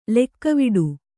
♪ lekkaviḍu